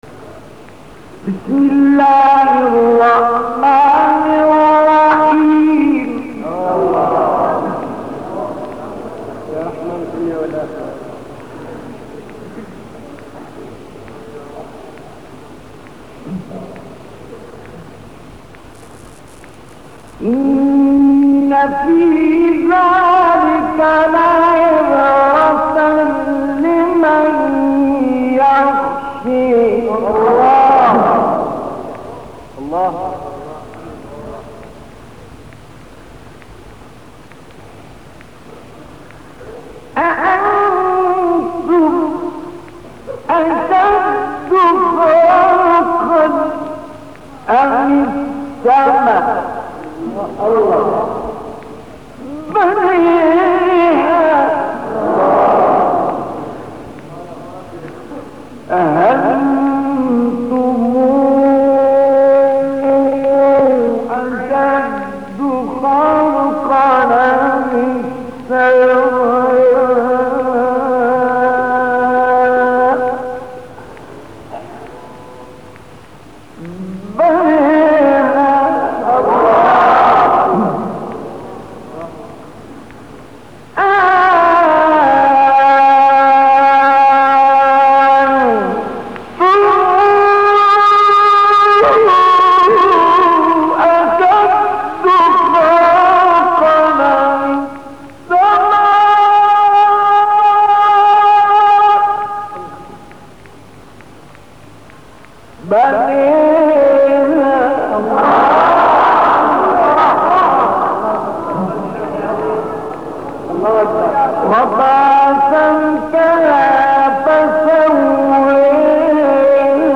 گروه فعالیت‌های قرآنی: تلاوت استاد مصطفی اسماعیل از سوره مبارکه نازعات که در سه مقام اجرا شده است.
تلاوت زیر که در مقامات رست، چهارگاه و عجم اجرا شده، در کانال بین‌المللی قرآنی نسیم وحی منتشر شده است.